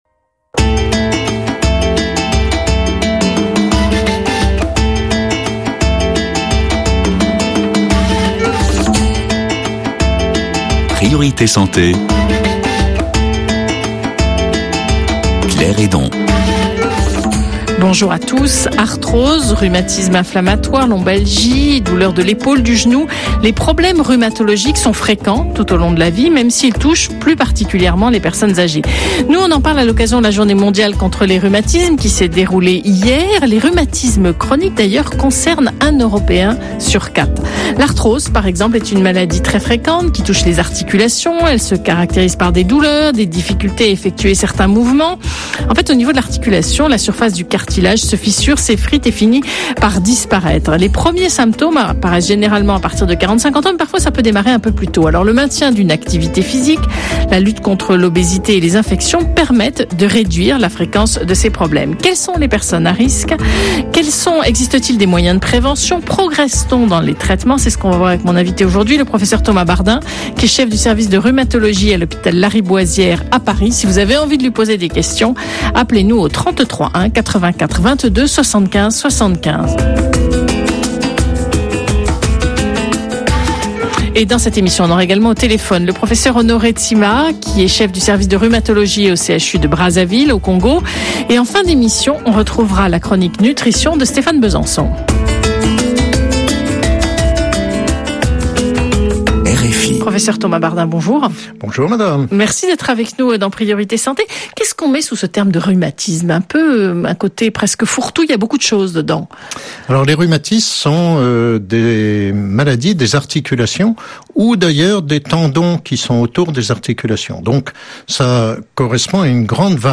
Travaillez avec vos apprenant(e)s sur cette interview d'un médecin qui explique les rhumatismes pour leur permettre d'avoir les compétences nécessaires pour expliquer une pathologie.